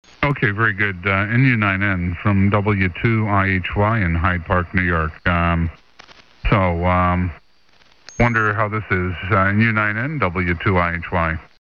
Extended SSB
広い帯域を使って素晴らしい音質でQSOしている局の音声です。